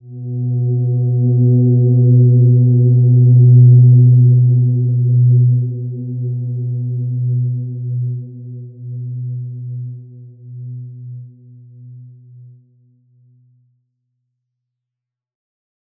Silver-Gem-B2-mf.wav